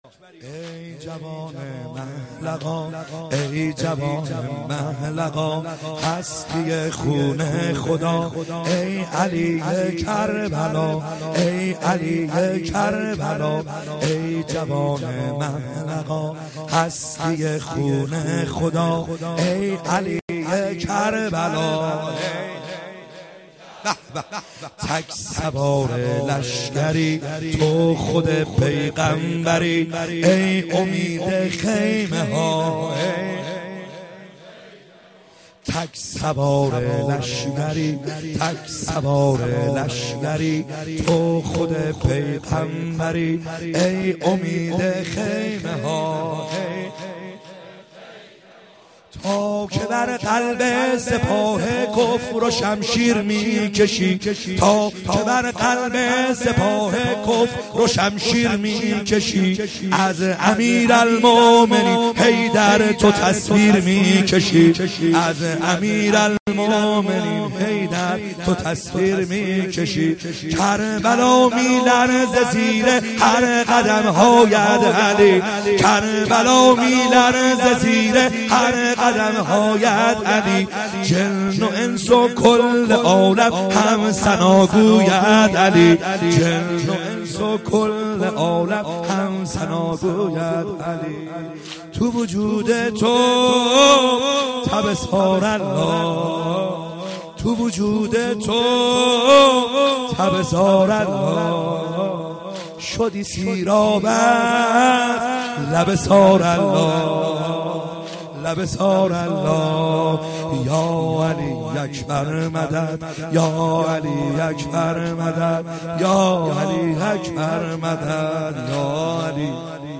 خیمه گاه - بیرق معظم محبین حضرت صاحب الزمان(عج) - واحد | ای جوان مه لقا